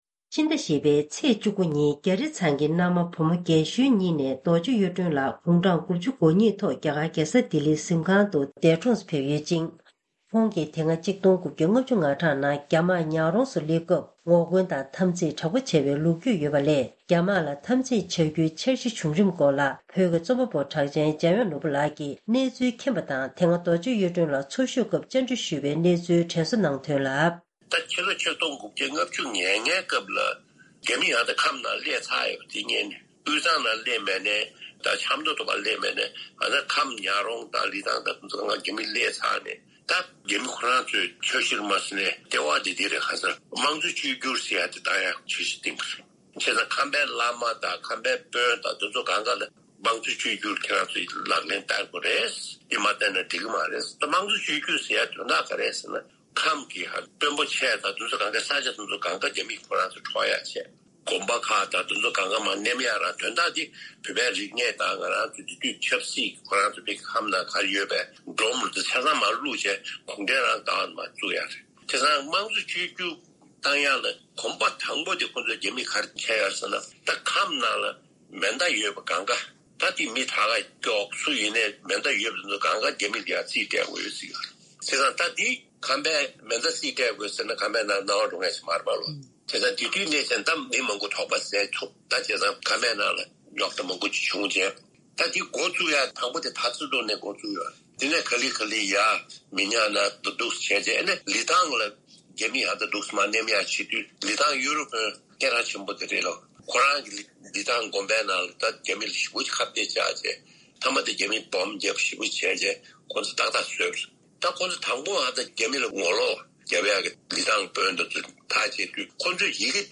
ཞེས་པའི་ཕྱག་དེབ་རྩོམ་པ་པོ་གྲགས་ཅན་འཇམ་དབྱངས་ནོར་བུ་ལགས་ཀྱིས་ངོ་སྤྲོད་གནང་བ་ཞིག་གསན་རོགས་གནང་།